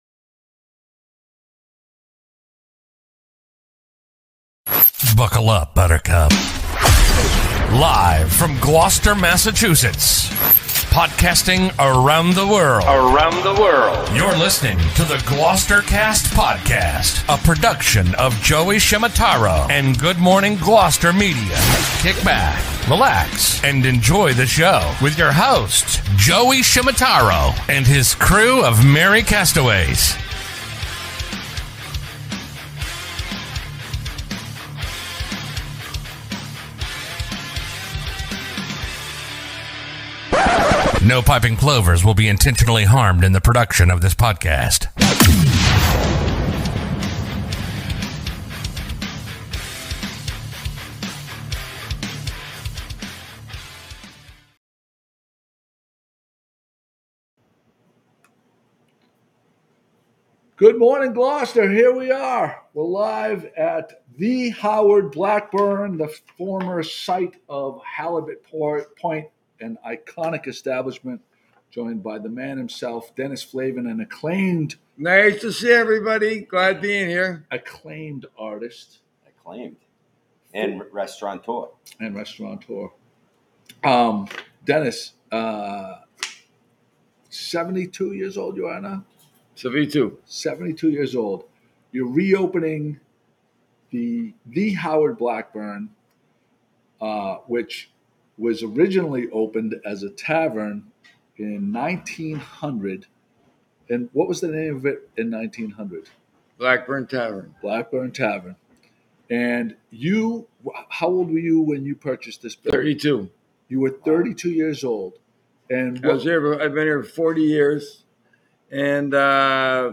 GloucesterCast 565 Live From The Howard Blackburn